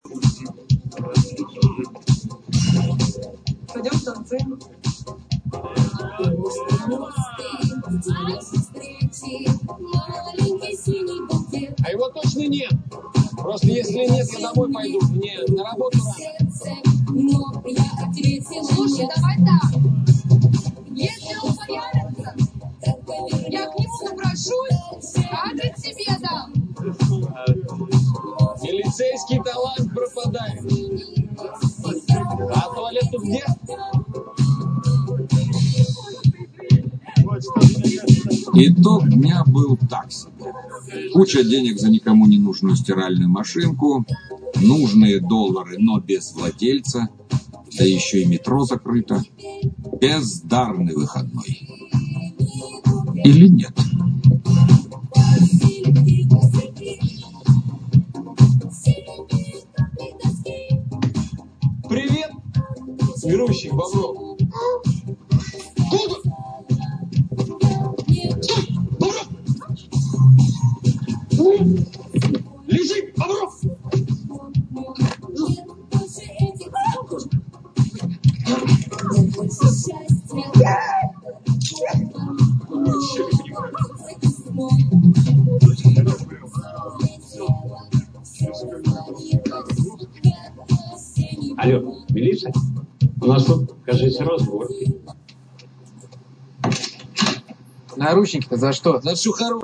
Стиль 90-х, а певицы молодые.